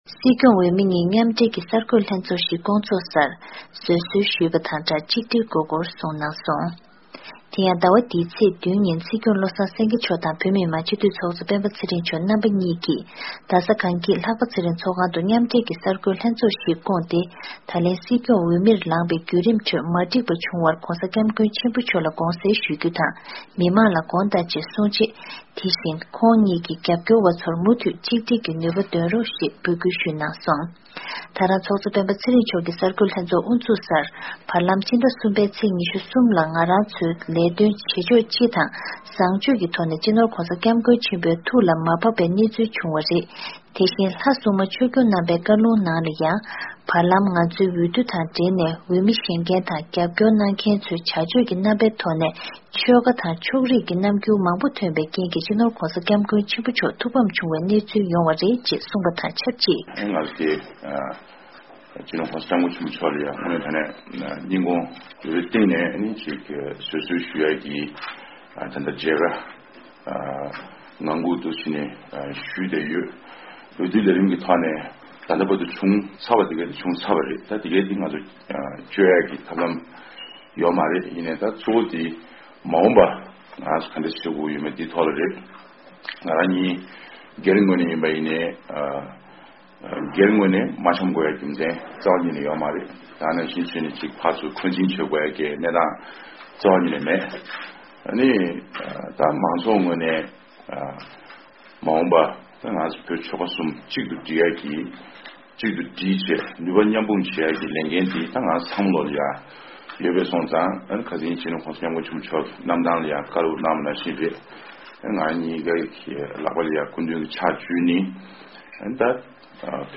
སྲིད་སྐྱོང་དང་ཚོགས་གཙོའི་གསར་འགོད་གསལ་བསྒྲགས།
ཕྱི་ཟླ་༤་ཚེས་༧་ཉིན་རྒྱ་གར་དྷ་རམ་ས་ལར་བཀའ་ཤག་སྐབས་བཅུ་བཞི་པའི་སྲིད་སྐྱོང་བློ་བཟང་སེངྒེ་དང་སྐབས་བཅོ་ལྔ་པའི་བོད་མི་དམངས་སྤྱི་འཐུས་ལྷན་ཚོགས་ཀྱི་ཚོགས་གཙོ་སྤེན་པ་ཚེ་རིང་གཉིས་ཀྱིས་མཉམ་འབྲེལ་གསར་འགོད་གསལ་བསྒྲགས་ཤིག་སྤེལ་ཏེ། ཁོང་རྣམ་པ་འདི་ལོ་སྲིད་སྐྱོང་གི་འོས་མིར་ལངས་པའི་བརྒྱུད་རིམ་ཁྲོད་དུ་མ་འགྲིགས་པ་དང མ་འཐུས་པ་བྱུང་ཡོད་པས་༧གོང་ས་སྐྱབས་མགོན་ཆེན་པོ་མཆོག་ལ་དགོངས་སེལ་ཞུ་རྒྱུ་དང་། བོད་མི་དམངས་ལའང་དགོངས་དག་ཞུ་རྒྱུ་ཡིན་ཞེས་གསུངས་ཡོད་པ་མ་ཟད། སྲིད་སྐྱོང་དང་ཚོགས་གཙོ་གཉིས་ཀྱིས་ད་ནས་བཟུང་སྟེ་བོད་པ་ཚོ་མཐུན་སྒྲིལ་རྡོག་རྩ་གཅིག་སྒྲིལ་བྱ་དགོས་པ་དང་། བོད་མི་རིགས་ཀྱི་གཅིག་སྒྲིལ་དང་མཐུན་སྒྲིལ་ལ་གནོད་པའི་ལས་འགུལ་གྱི་རིགས་མཐའ་དག་ངེས་པར་དུ་སྤེལ་རྒྱུ་མཚམས་འཇོག་བྱ་དགོས་ཞེས་འབོད་སྐུལ་ནན་པོ་ཞུས་འདུག